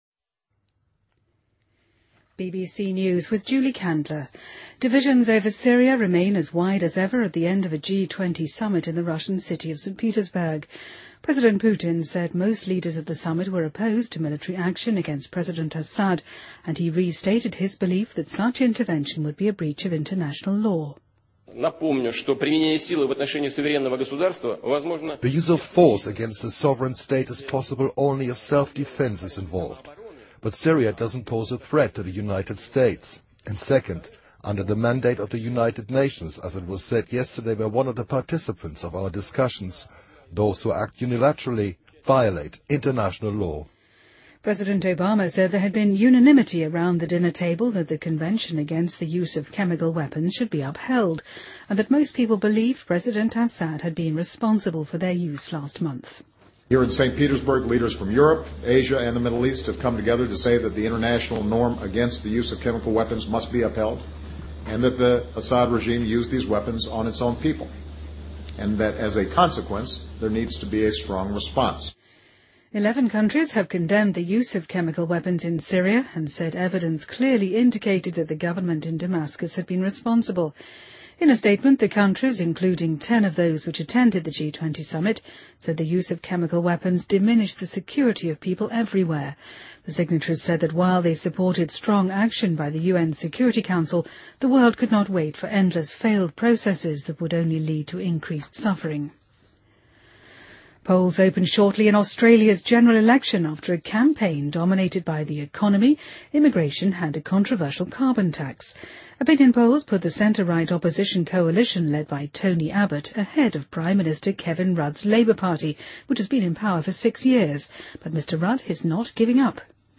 BBC news,津巴布韦当局逮捕了涉嫌用氰化物毒药杀死40多头大象的嫌犯